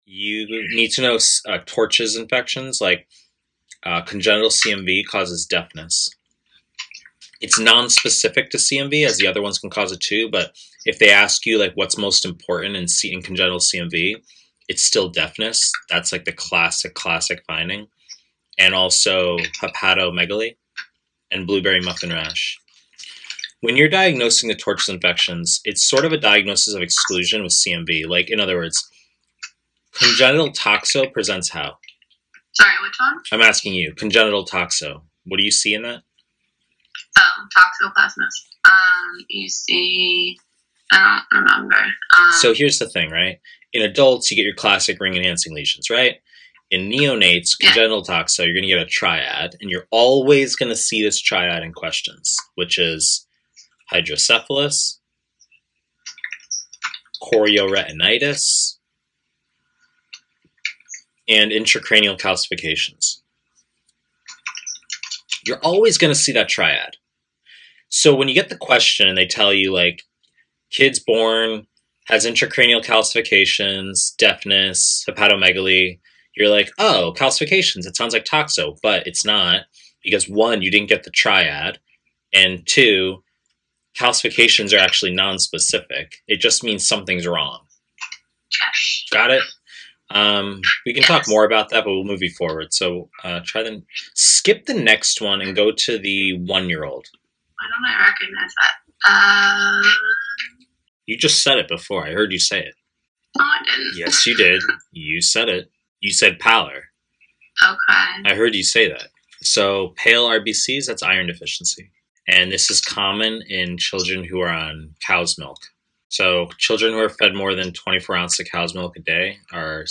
Pediatrics / Pre-recorded lectures